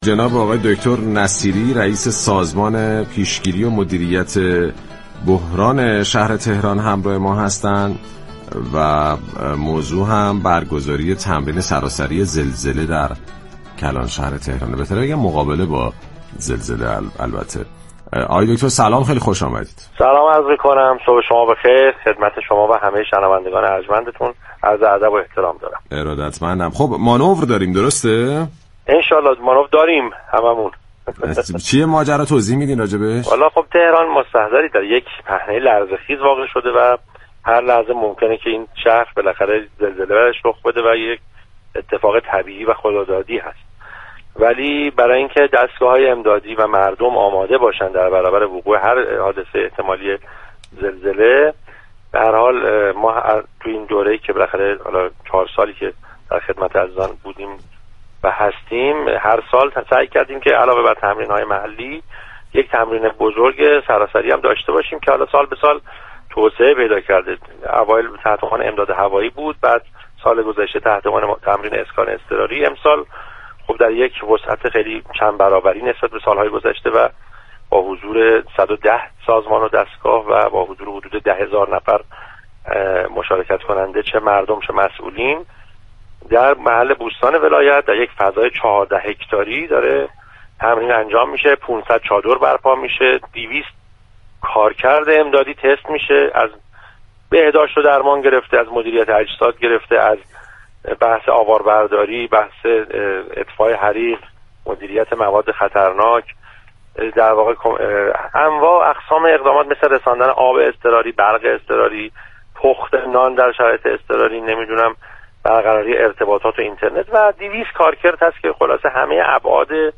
به گزارش پایگاه اطلاع رسانی رادیو تهران، علی ‌نصیری رئیس سازمان پیشگیری و مدیریت بحران شهر تهران در گفت و گو با «بام تهران» اظهار داشت: تهران در پهنه لرزه خیر قرار گرفته است، برای اینكه مردم و دستگاه‌های امدادی در برابر وقع زلزله آماده باشند در دوره چهار ساله گذشته سعی كردیم علاوه بر تمرین‌های محلی هر سال یك تمرین بزرگ سراسری داشته باشیم و نسبت به سال گذشته آن را توسعه دهیم.